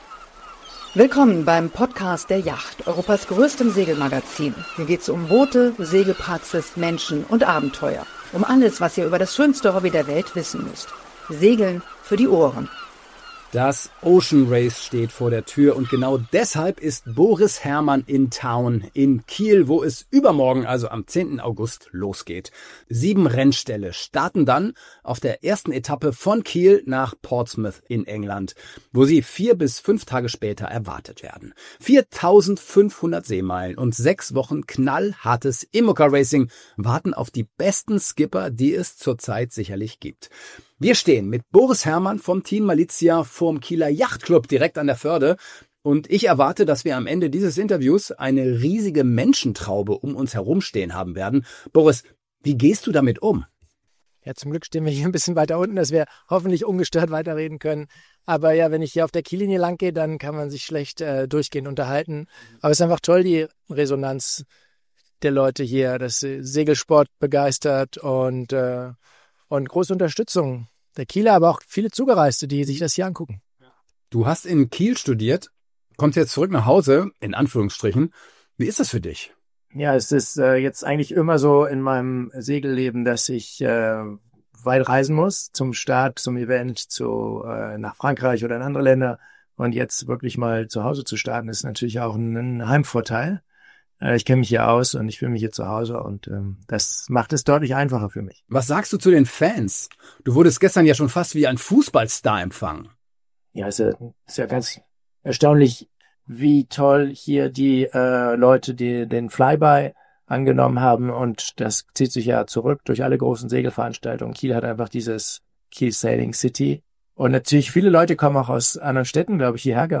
Segelprofi Boris Herrmann